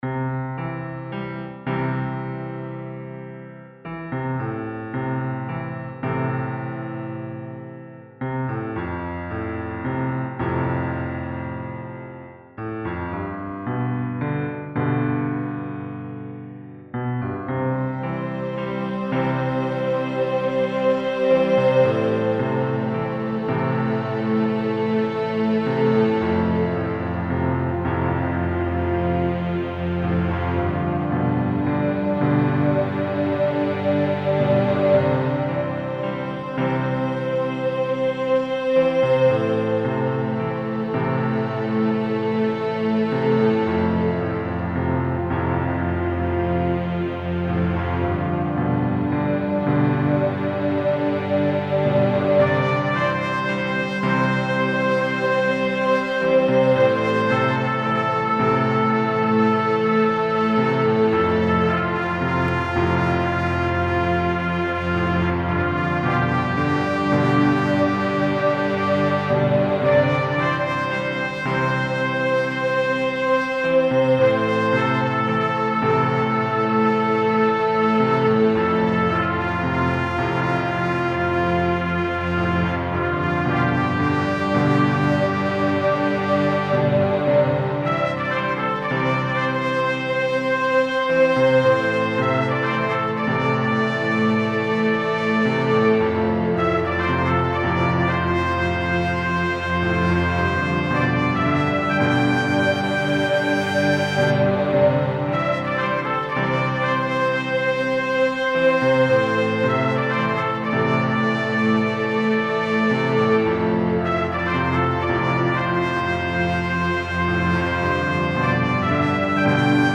orchestral piece piano-violins and trumpets
orch1.mp3